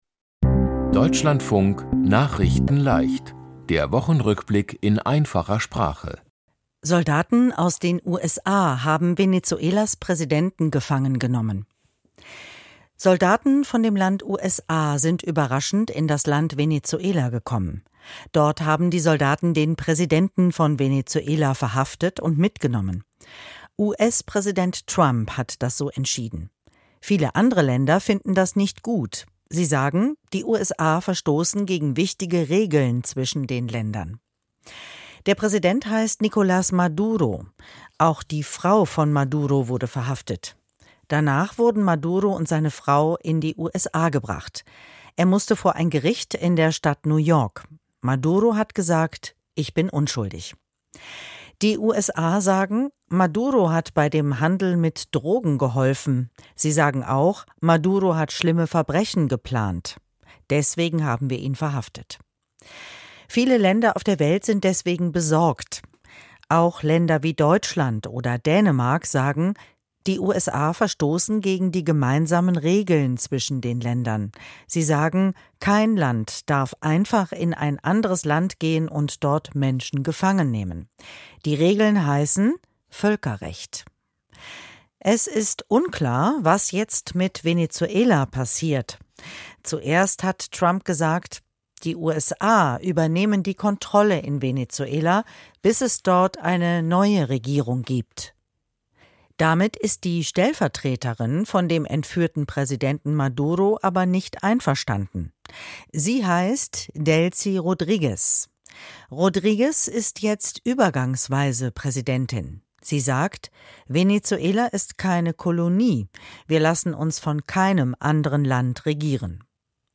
Die Themen diese Woche: Soldaten aus den USA haben Venezuelas Präsidenten gefangen genommen, Sorge in Grönland vor Militär-Einsatz von den USA, Alle Haushalte in Berlin haben wieder Strom, Brandenburg: SPD beendet Koalition mit BSW, Das Heilige Jahr ist zu Ende, und Slowene Prevc gewinnt 4-Schanzen-Tournee. nachrichtenleicht - der Wochenrückblick in einfacher Sprache.